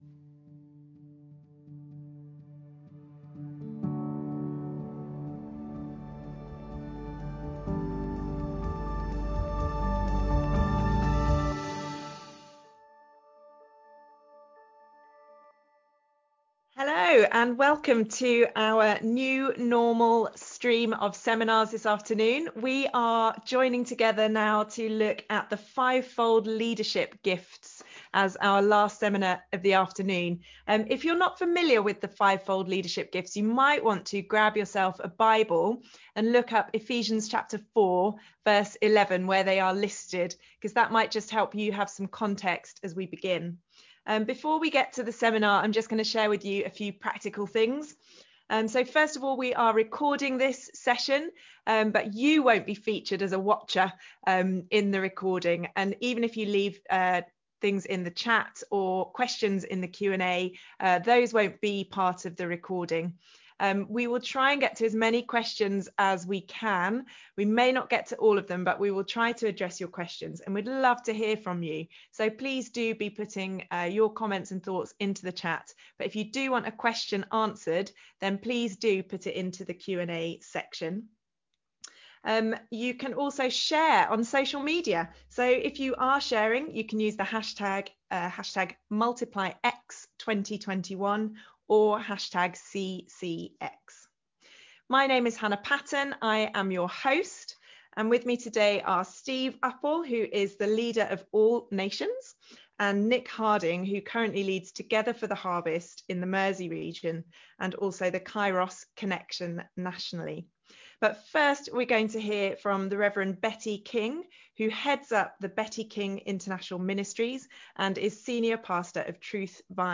Multiply 2021 Seminars: Five-fold leadership gifts – CCX